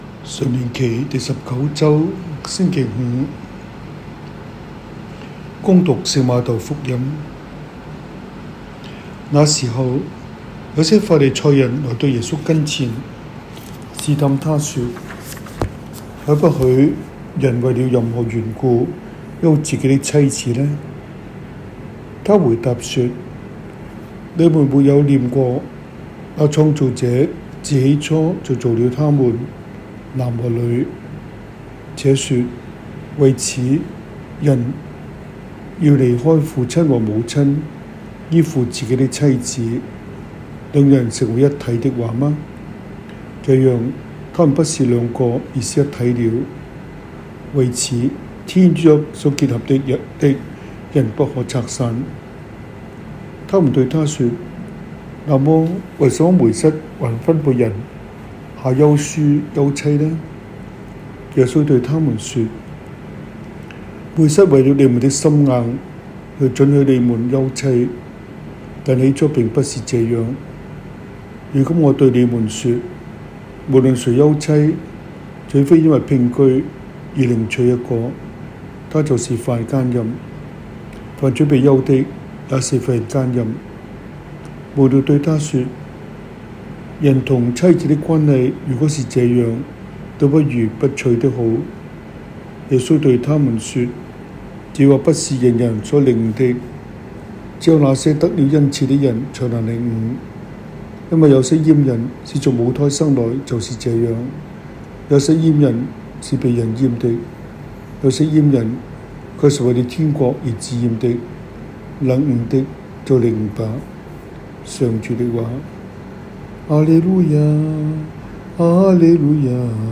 中文講道, 英文講道